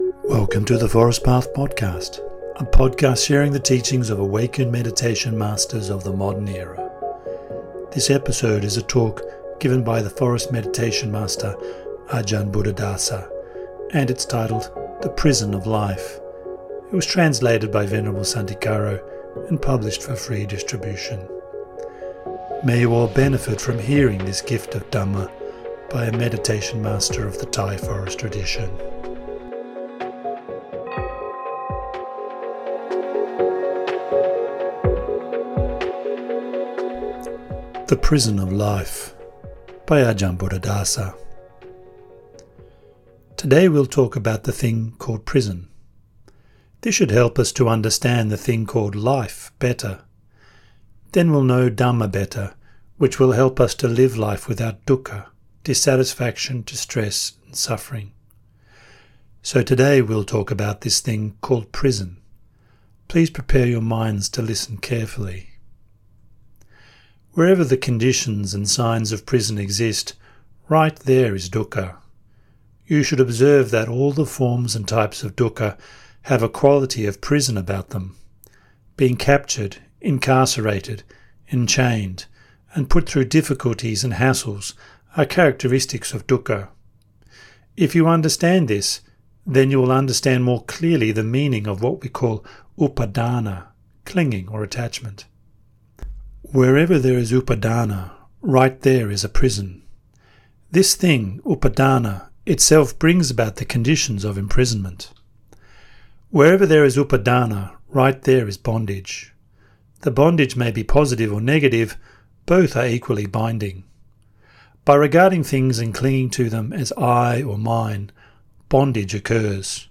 This episode is a talk given by the forest meditation master Ajahn Buddhadasa and is titled “The Prison of Life” .